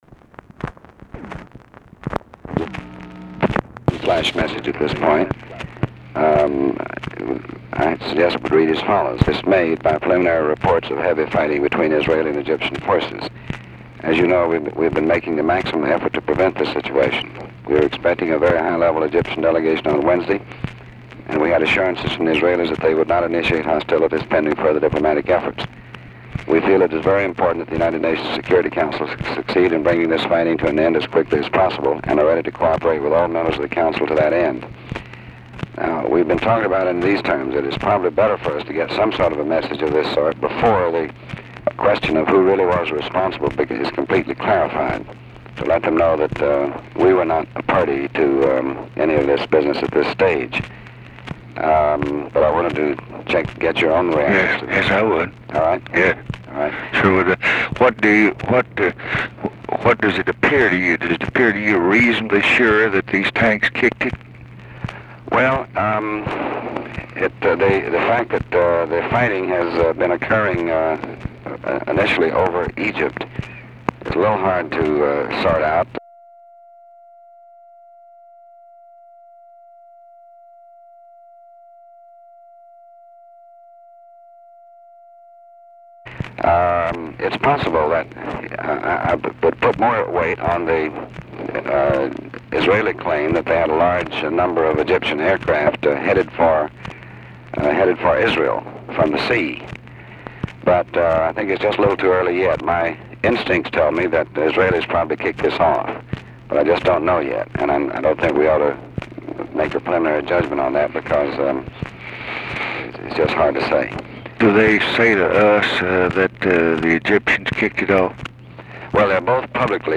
Conversation with DEAN RUSK, June 5, 1967
Secret White House Tapes